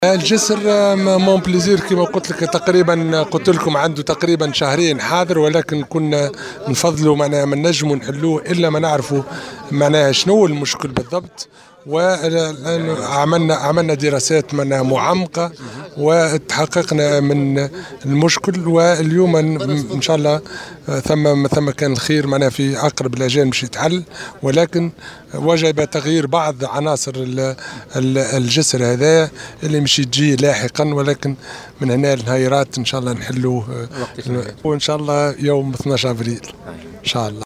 وأفاد على هامش تصريحات صحفية على هامش زيارة رسمية أداها إلى ولاية بنزرت، أن إعادة فتح الجسر ستتم يوم 12 أفريل 2016.